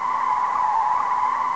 skidquiet.wav